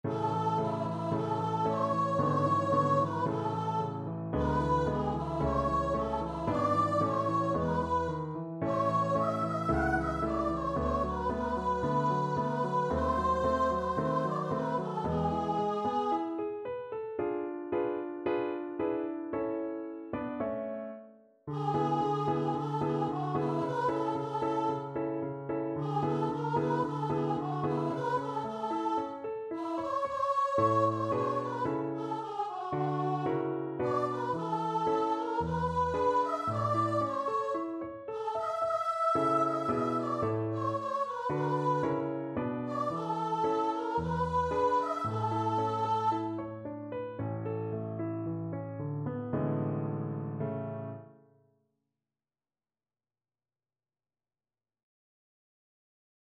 Voice
A major (Sounding Pitch) (View more A major Music for Voice )
~ = 56 Affettuoso
2/4 (View more 2/4 Music)
Classical (View more Classical Voice Music)